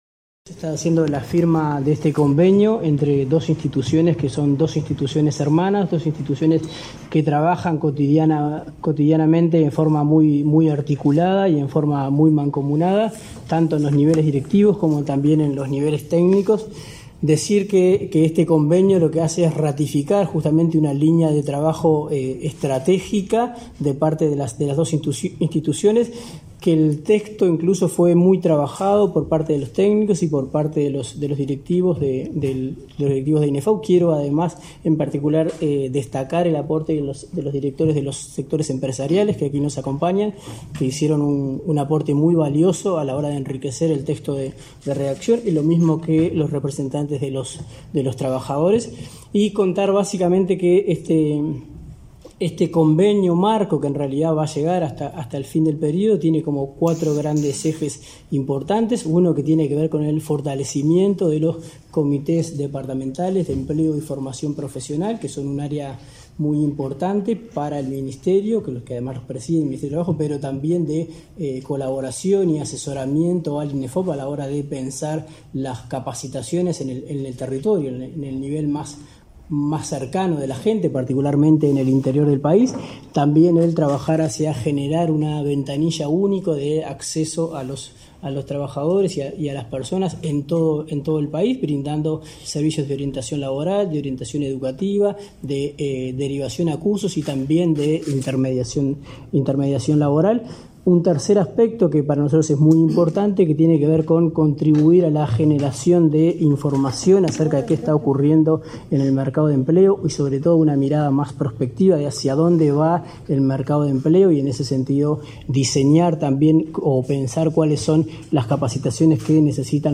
Conferencia de prensa por la firma de convenio entre el Ministerio de Trabajo y el Inefop